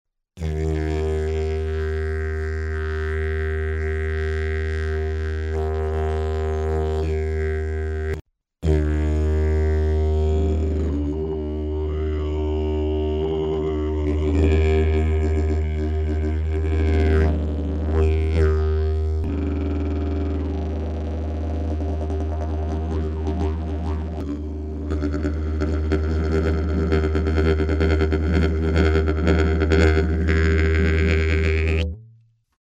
Диджериду (бук)
Диджериду (бук) Тональность: D#
Длина (см): 156